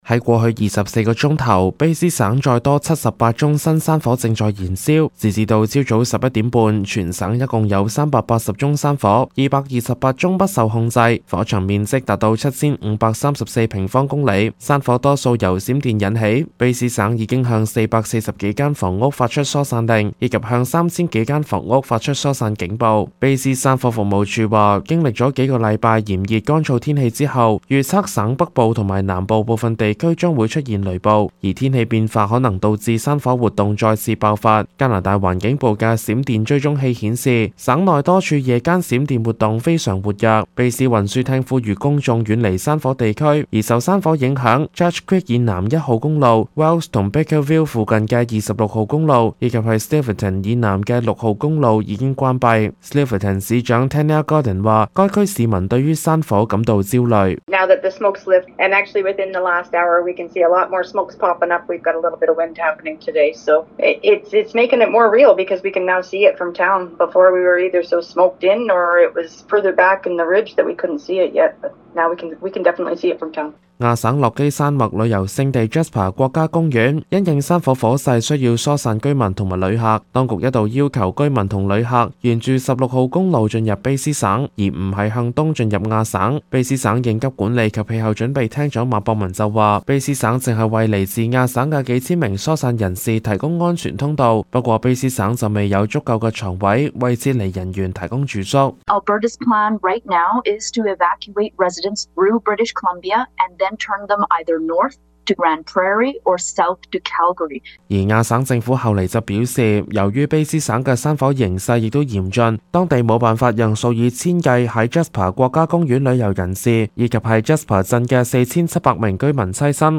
粵語